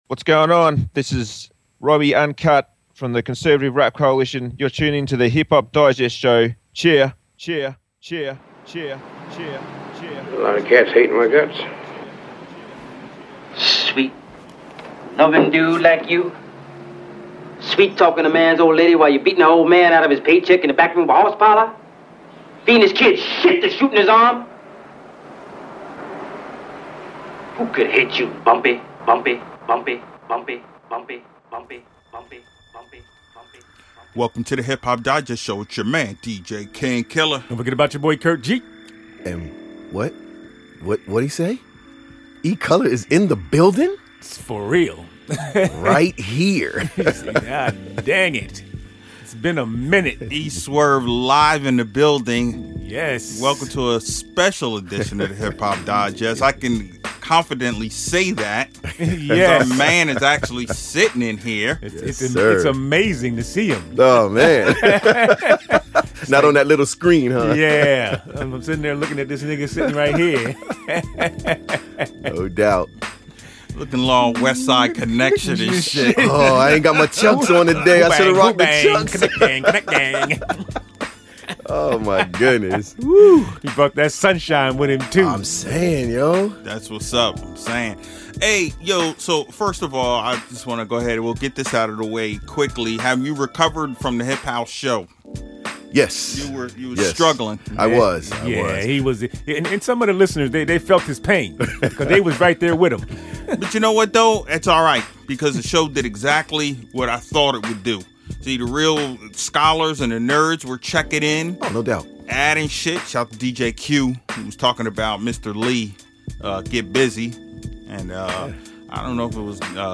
First we gotta say – It was awesome having all three of us in the lab to record this week!